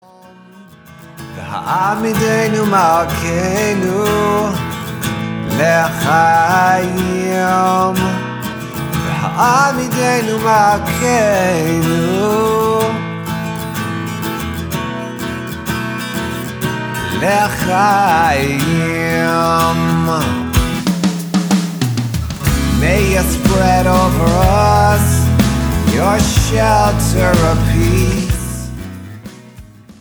deep rhythms and sweet harmonies